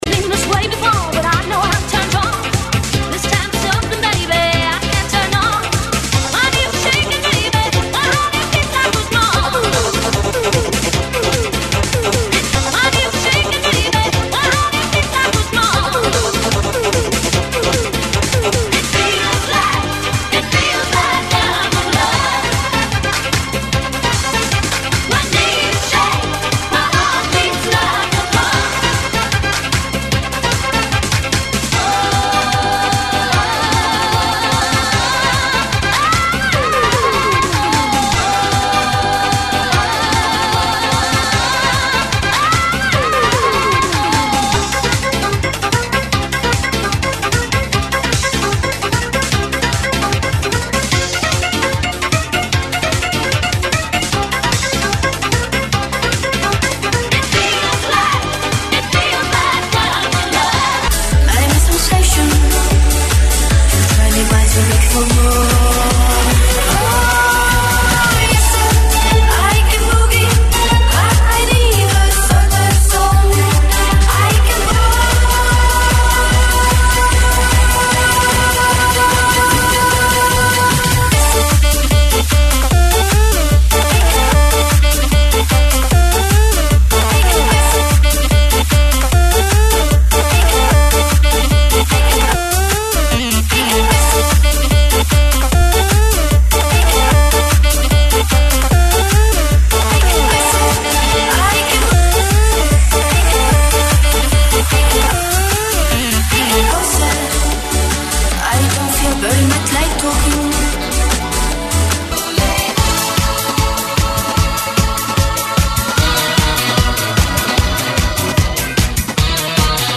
GENERO: MUSICA DISCO
AEROBICS (STEP-HILOW)